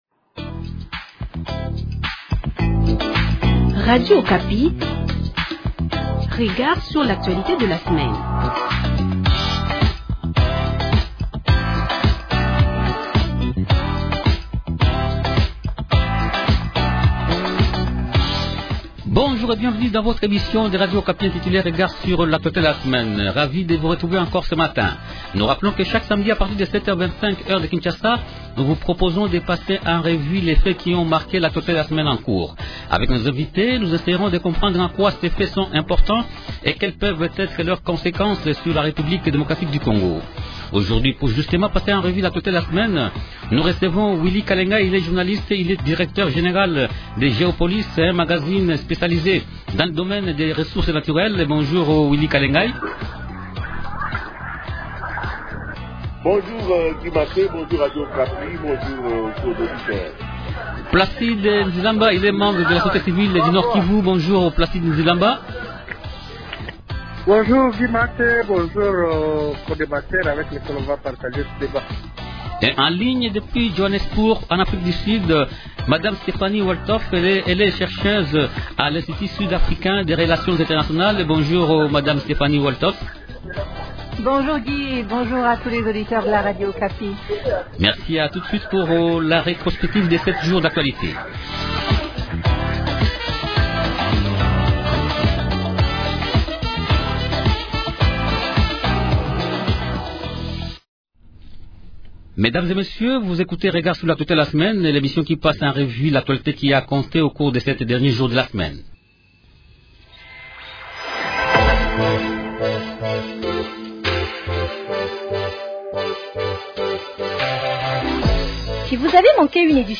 Trois sujets principaux au menu de cette émission.